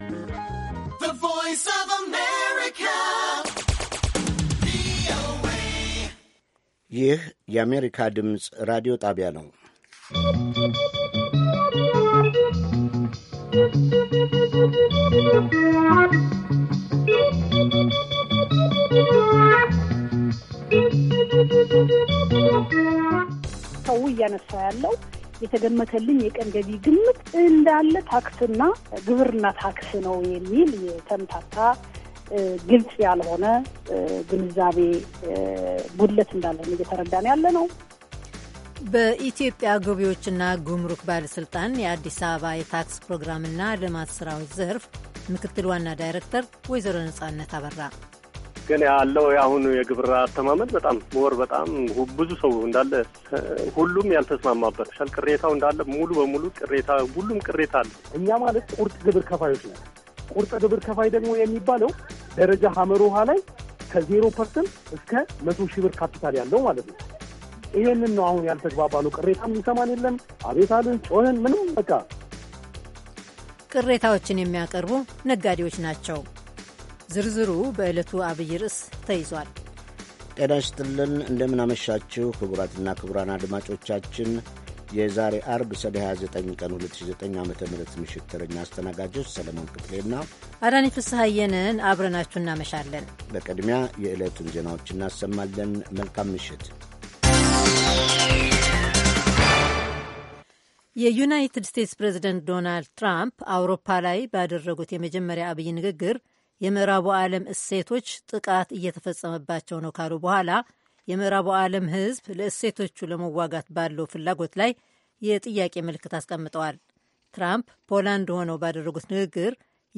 ሐሙስ፡-ከምሽቱ ሦስት ሰዓት የአማርኛ ዜና